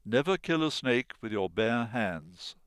Sounds for Exercise I Chapter 4 spoken by a British Speaker